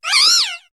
Cri de Gaulet dans Pokémon HOME.